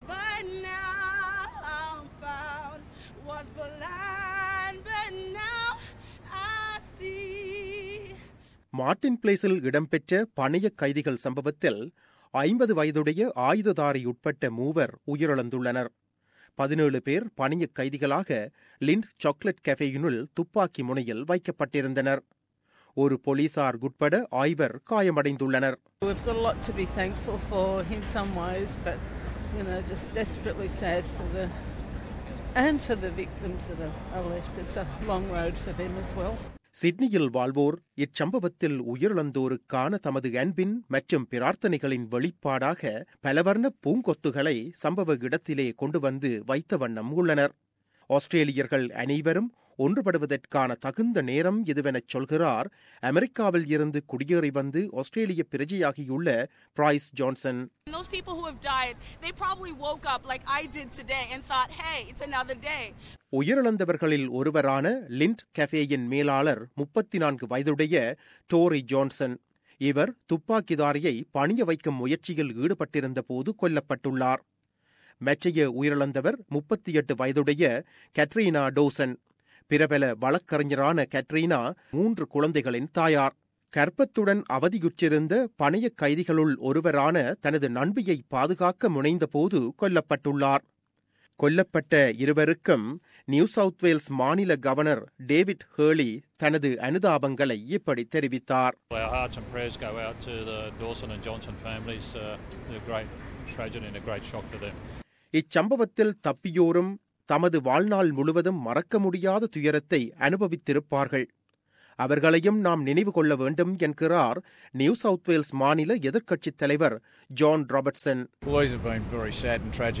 தாக்குதலுக்குப் பின்னரான நிலவரம் பற்றிய ஒரு விவரணம்.